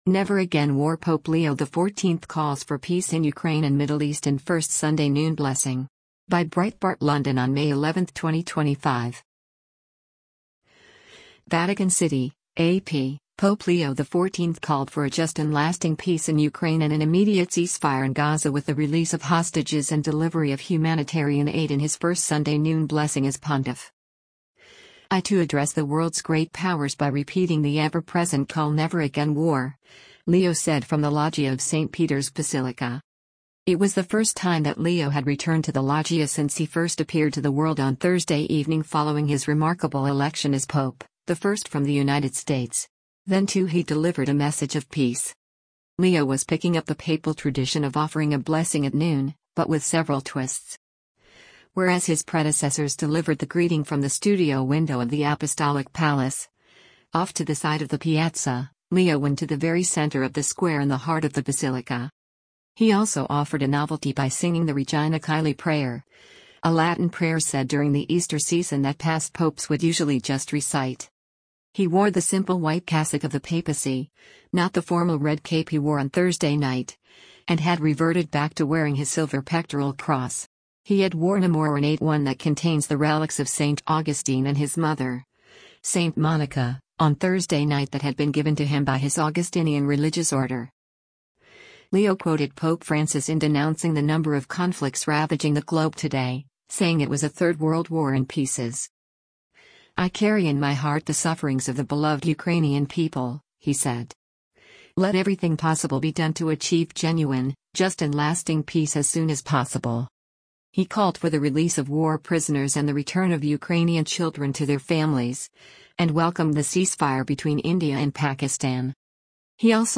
Pope Leo XIV greets the crowd after the Regina Caeli prayer from the main central loggia b
He also offered a novelty by singing the Regina Caeli prayer, a Latin prayer said during the Easter season that past popes would usually just recite.
The crowd, filled with marching bands in town for a special Jubilee weekend, erupted in cheers and music as the bells of St. Peter’s Basilica tolled.